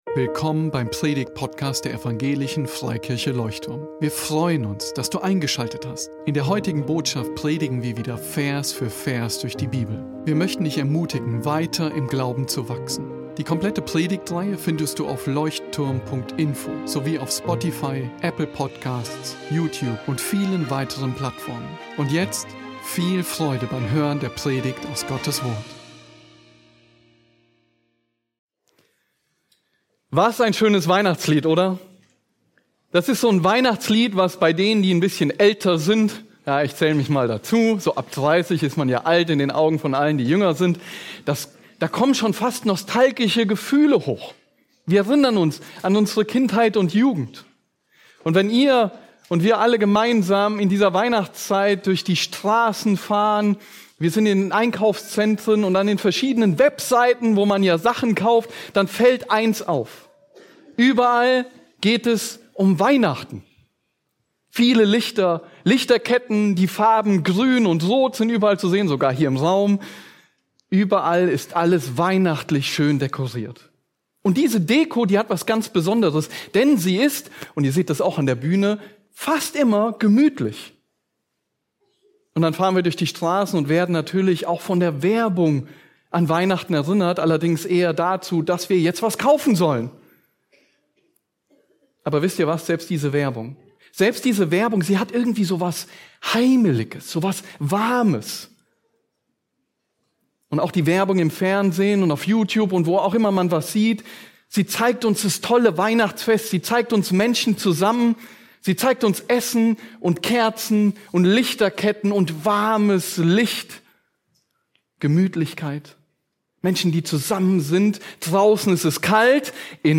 Heiligabend Gottensdienst am 24.12.2025 Besuche unseren Gottesdienst in Berlin.